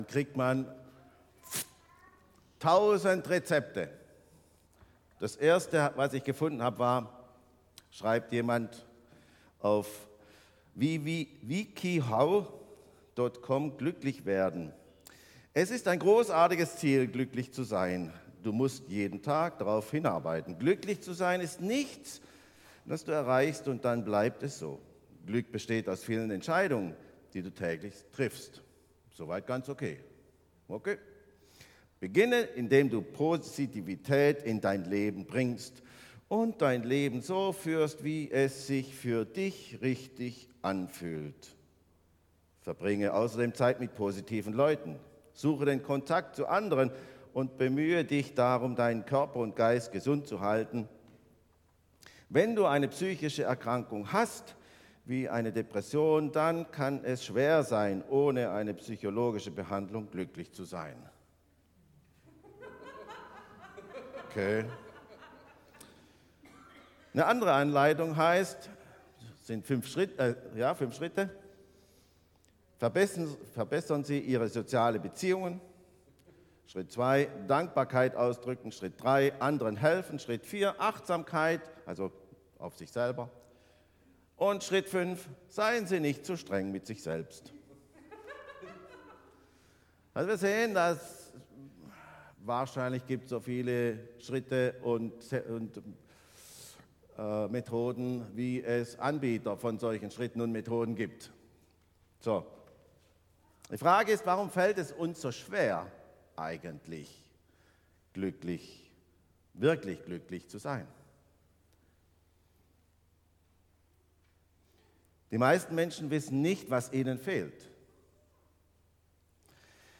regiogemeinde - Predigten